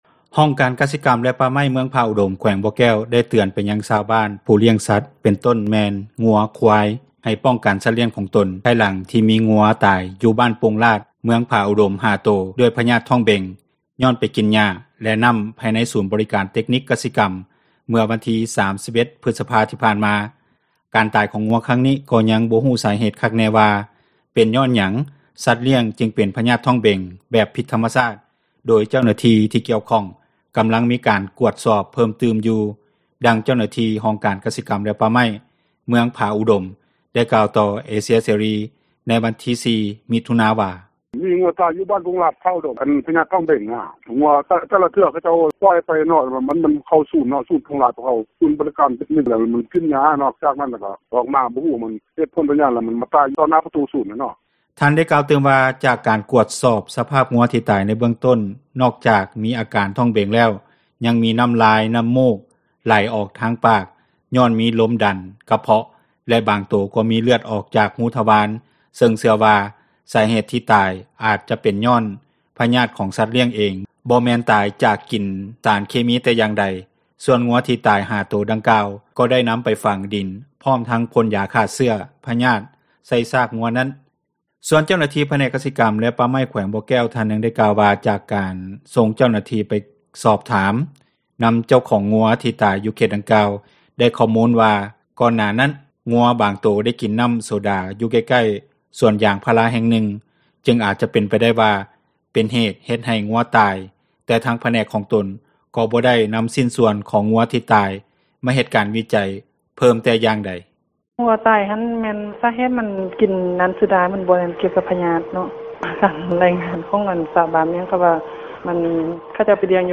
ດັ່ງ ເຈົ້າໜ້າທີ່ຫ້ອງ ກະສິກັມ ແລະປ່າໄມ້ ເມືອງຜາອຸດົມ ໄດ້ກ່າວ ຕໍ່ວິທຍຸເອເຊັຽເສຣີ ໃນວັນທີ 04 ມິຖຸນາ ວ່າ.